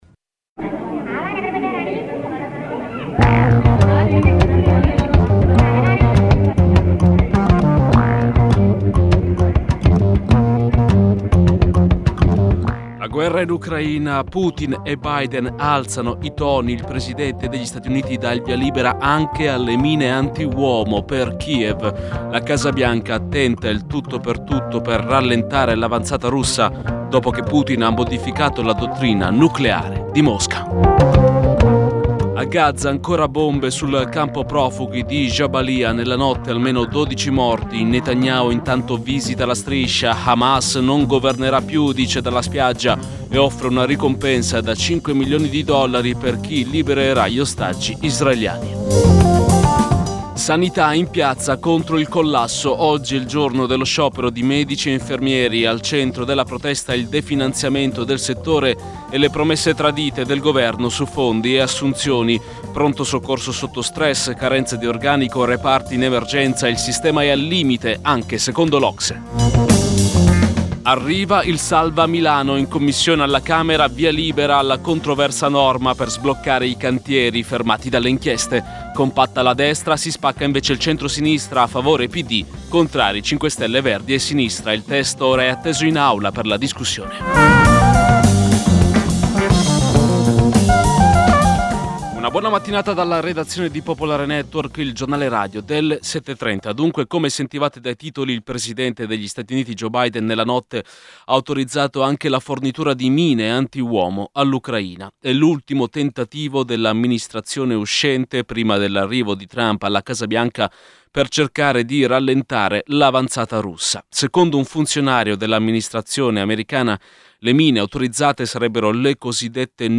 Giornale radio Nazionale